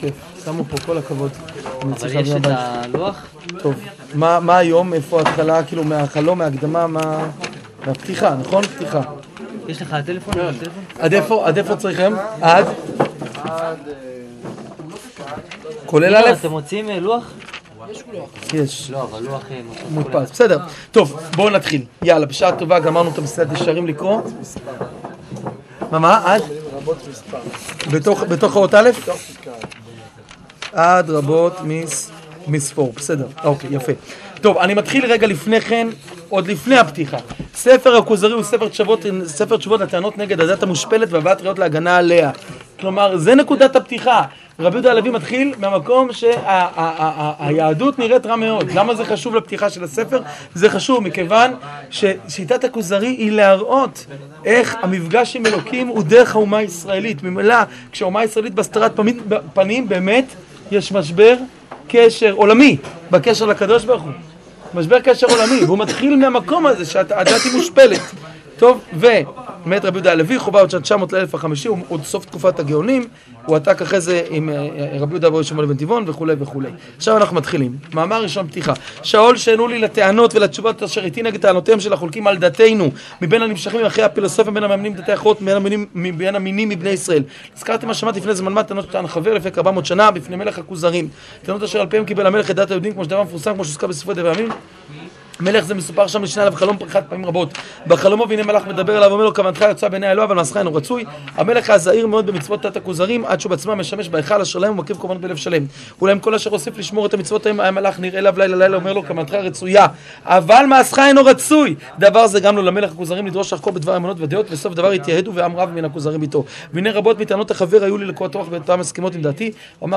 שיעור פתיחה